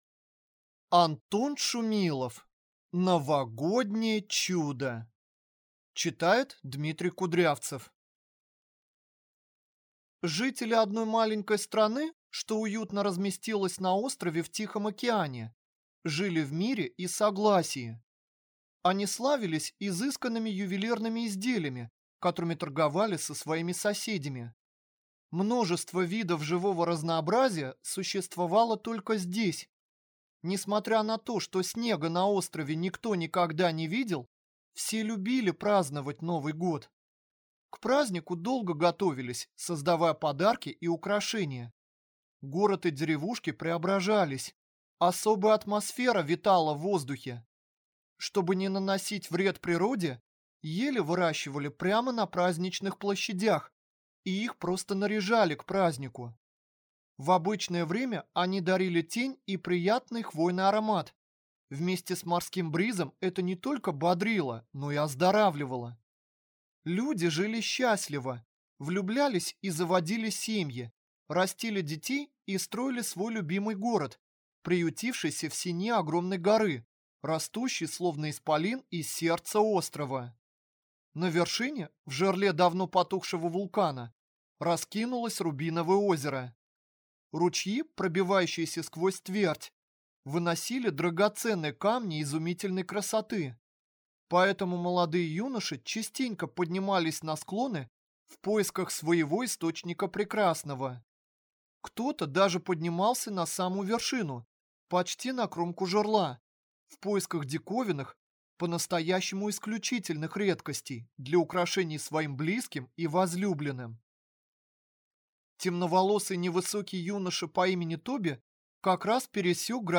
Аудиокнига Новогоднее чудо | Библиотека аудиокниг